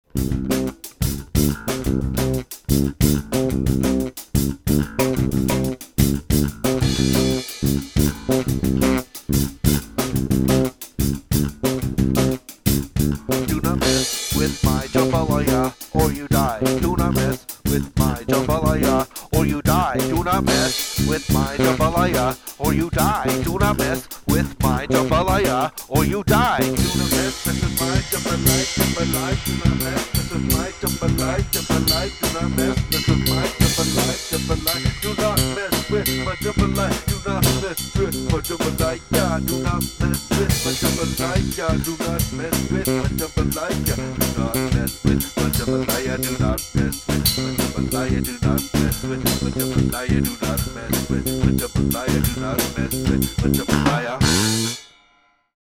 Many of the vocal tracks are improvised lyrics and melodies.